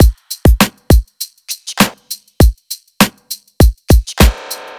FK100BEAT2-R.wav